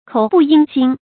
口不應心 注音： ㄎㄡˇ ㄅㄨˋ ㄧㄥ ㄒㄧㄣ 讀音讀法： 意思解釋： 應：符合。